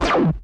ballsLaser.ogg